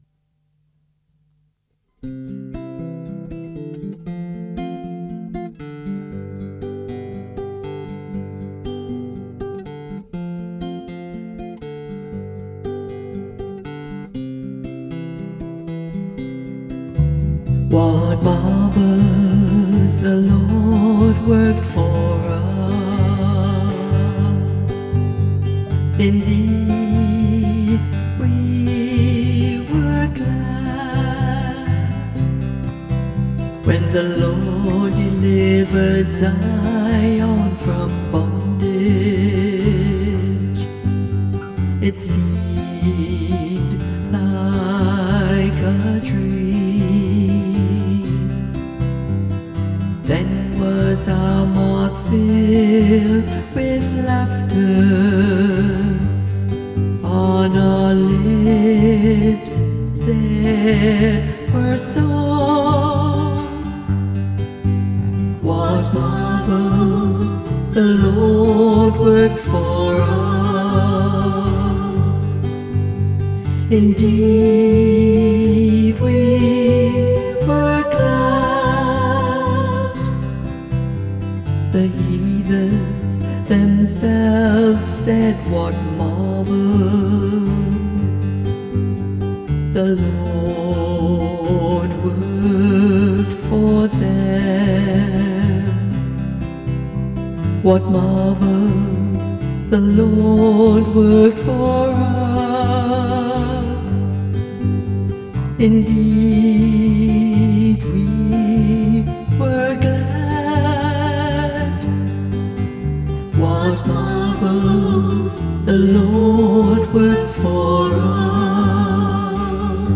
Responsorial Psalm 125(126)
Music by the Choir of Our Lady of the Rosary RC Church, Verdun, St. John, Barbados.